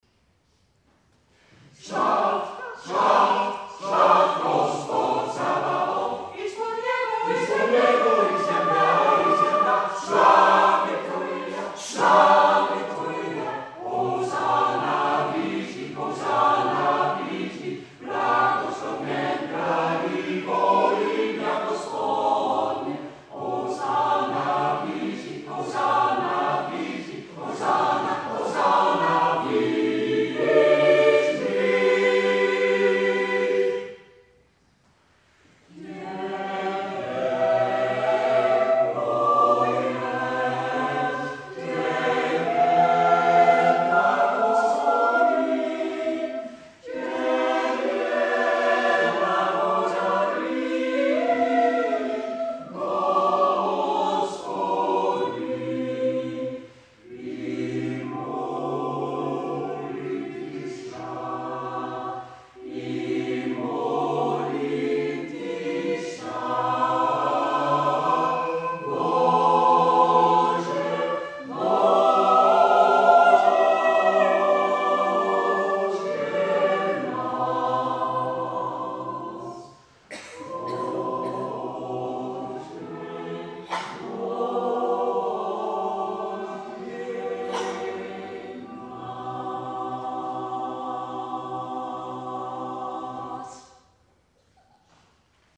Byzantijns Koor